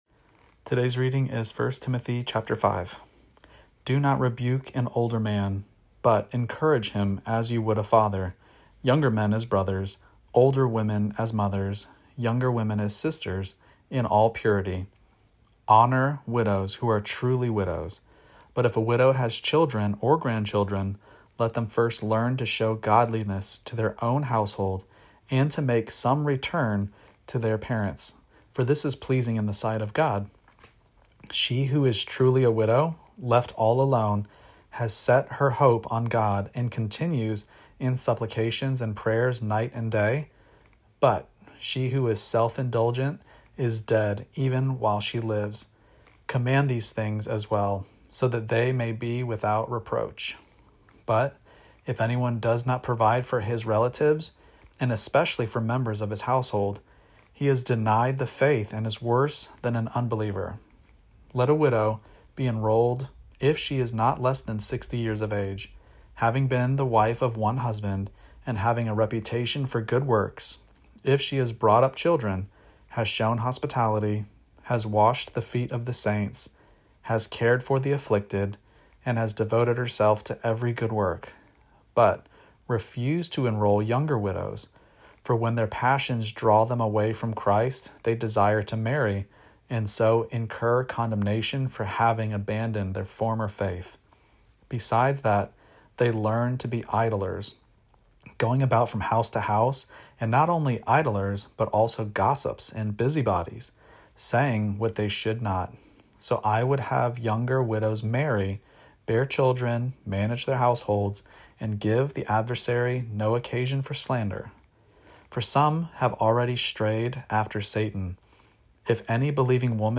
Daily Bible Reading (ESV) October 25: 1 Timothy 5 Play Episode Pause Episode Mute/Unmute Episode Rewind 10 Seconds 1x Fast Forward 30 seconds 00:00 / 3:25 Subscribe Share Apple Podcasts Spotify RSS Feed Share Link Embed